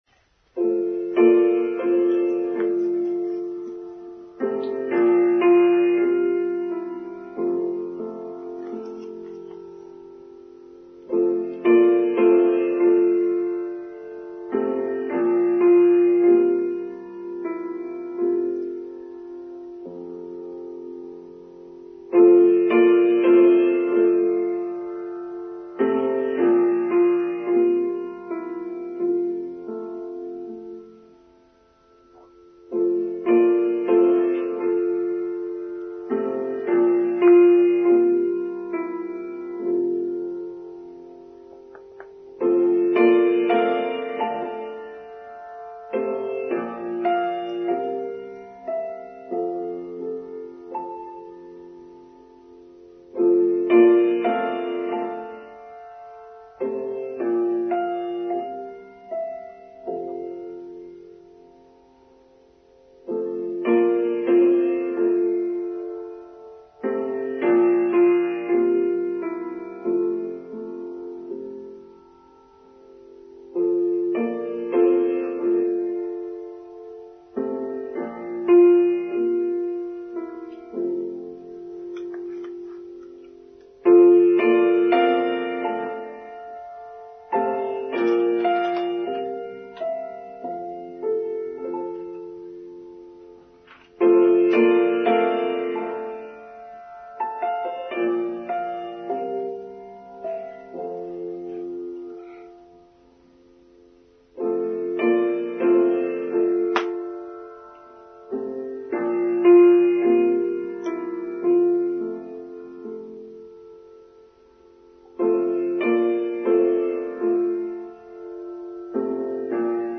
(my apologies if the quality of sound is not so good as usual – we had a terrific rainstorm half way through recording the service!)